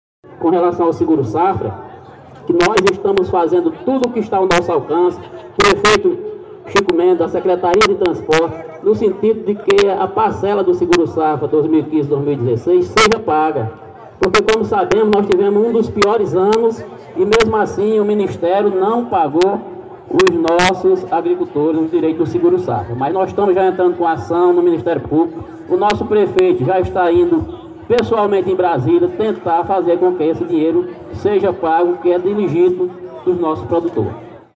O secretário da Agricultura de São José de Piranhas, Antônio de Haroldo, disse durante discurso, esta semana, que a Prefeitura está lutando para que os agricultores familiares do município recebam o Garantia-Safra 2015/2016 do governo federal.